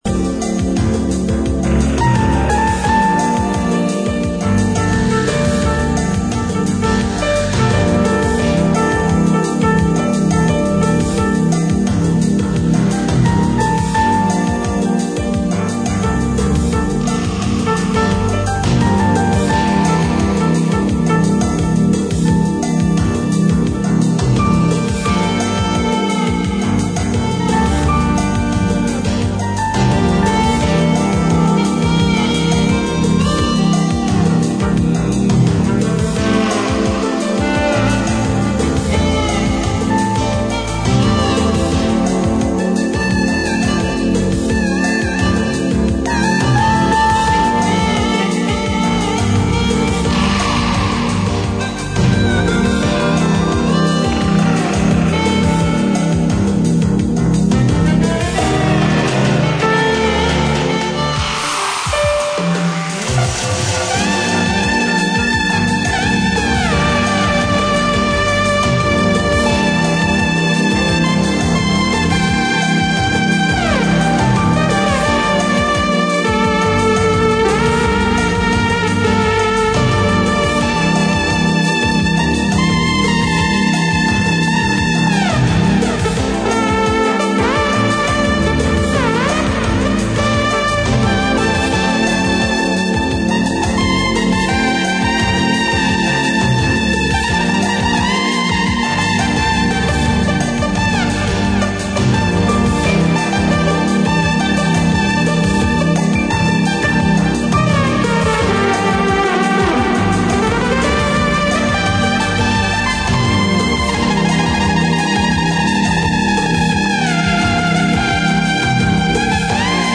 シンセやパーカッション、ギターなどがとろけるように絡み合う、正にバレアリックな最高のアルバム！